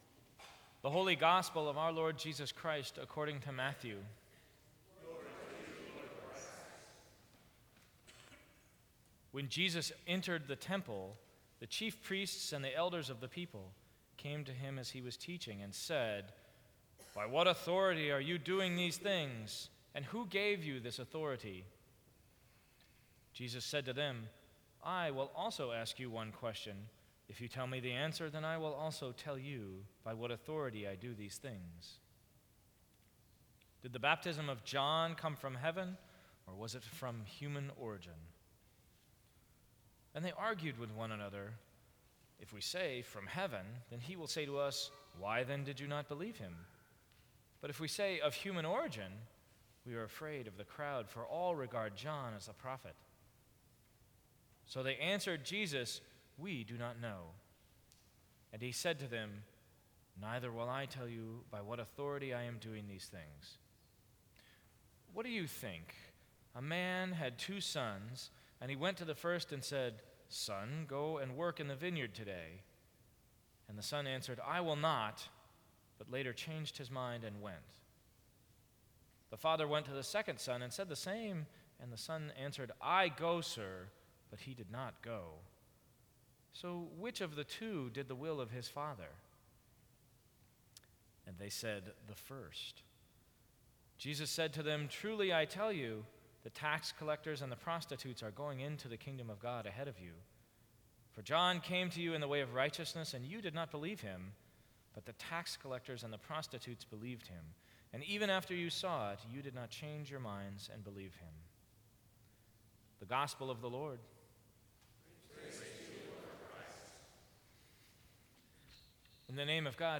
Sermons from St. Cross Episcopal Church September 28, 2014.